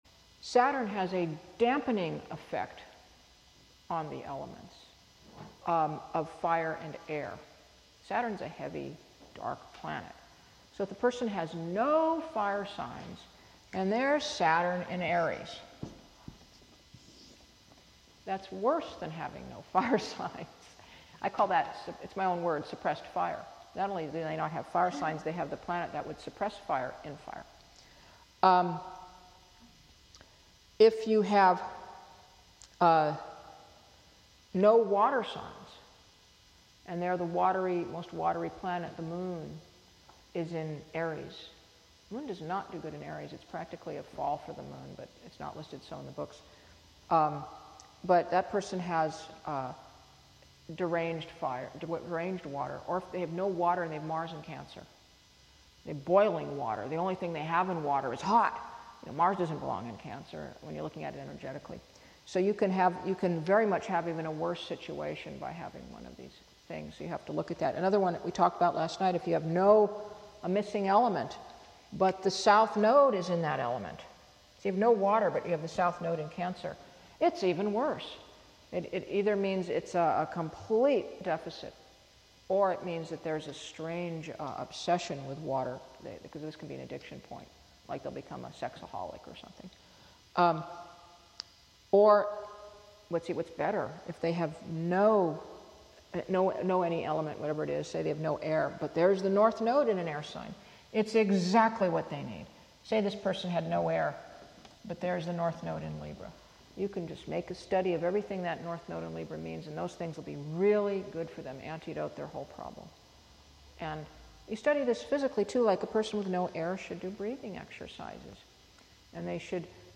Chart Reading Skills workshop